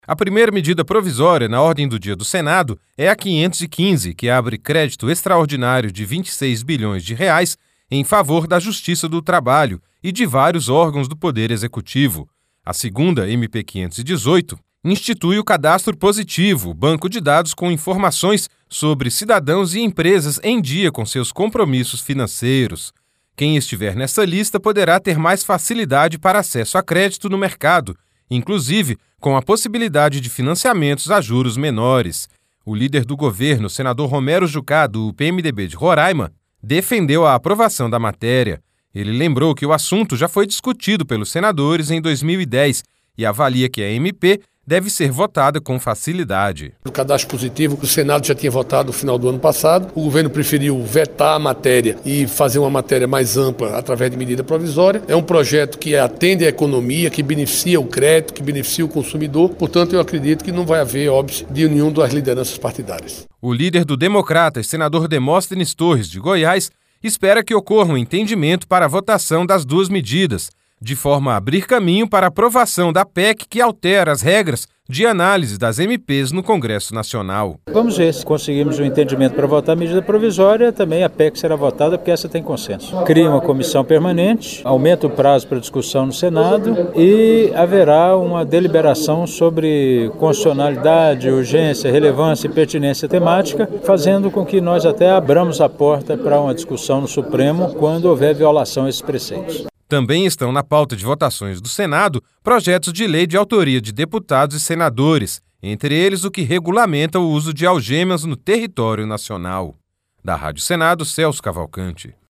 O líder do governo, senador Romero Jucá, do PMDB de Roraima, defendeu a aprovação da matéria.